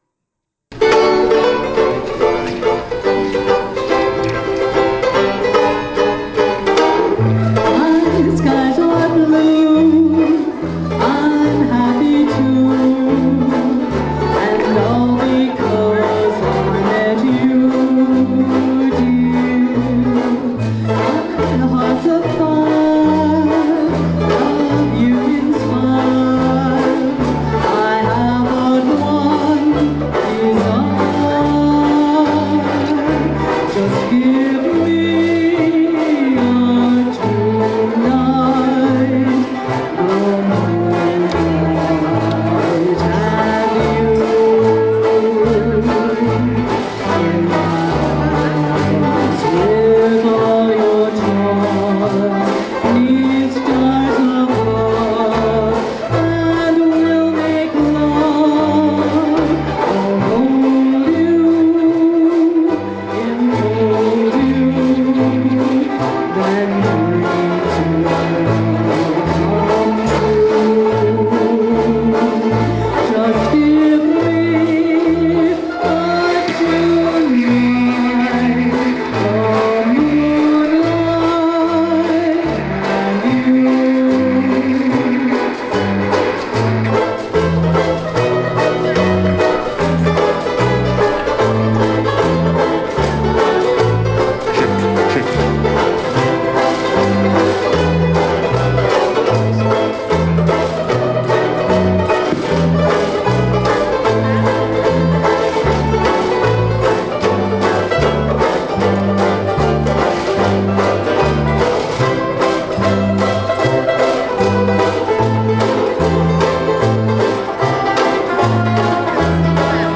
Vocal